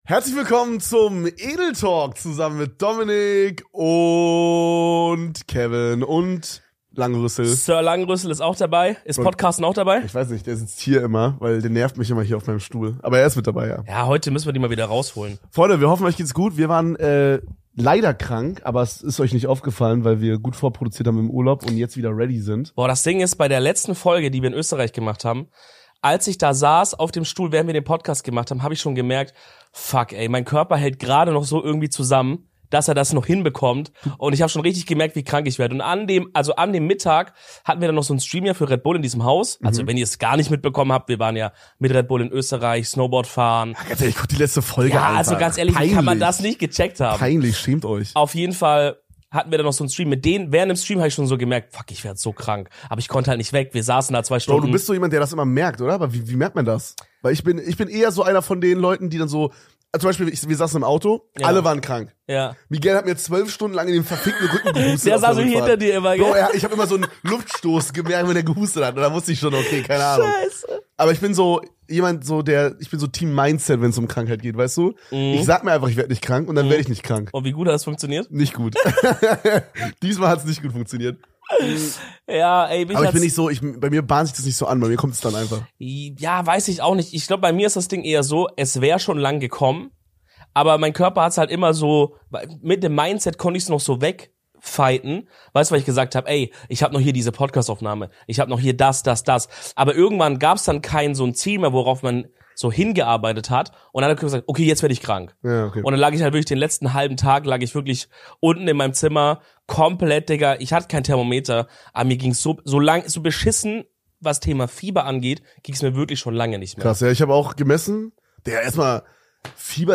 Etwas angekränkelt sind wir zurück in unserem Podcast Studio und präsentieren euch eine weitere Zweierfolge.